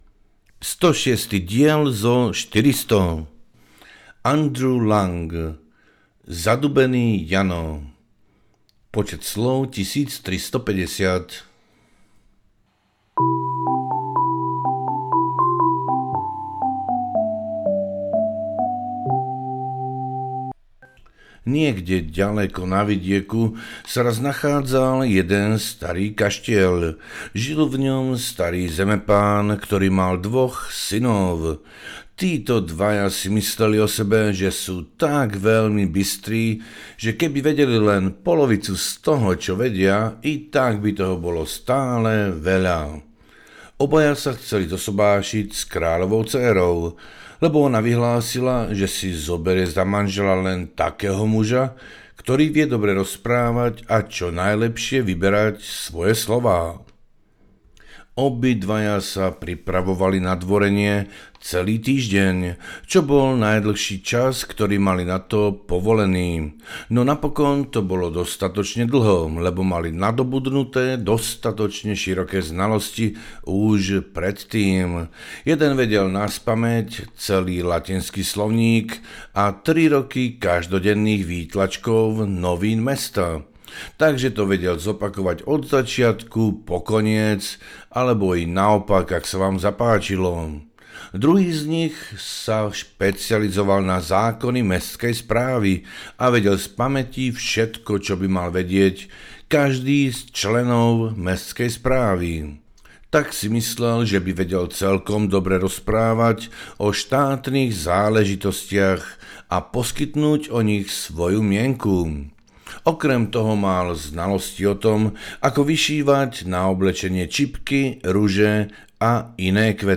77 poviedok na večery a noci audiokniha
Ukázka z knihy
77-poviedok-na-vecery-a-noci-audiokniha